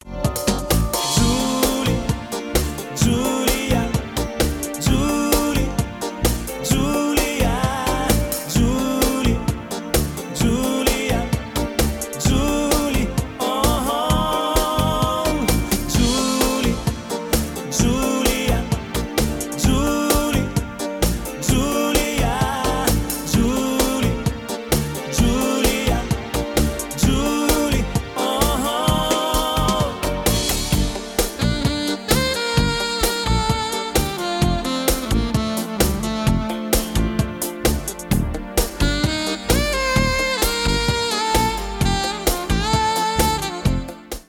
• Качество: 320, Stereo
поп
мужской вокал
Саксофон
романтичные